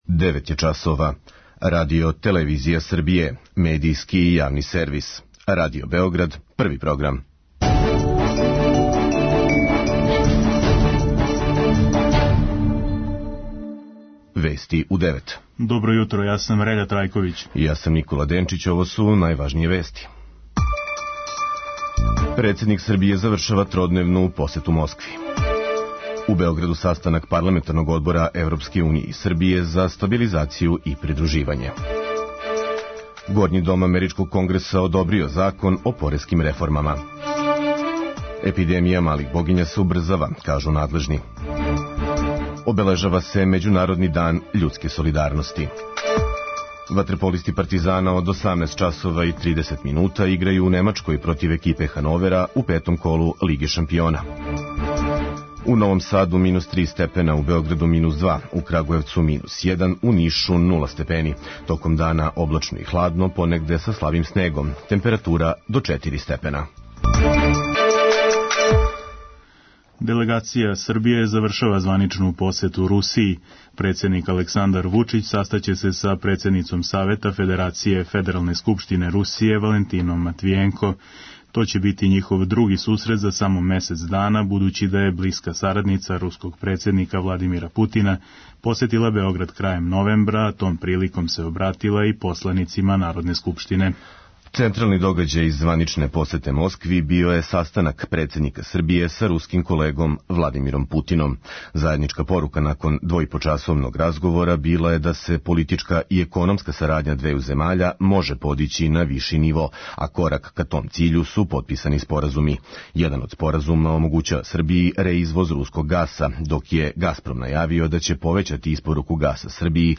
Вести у 9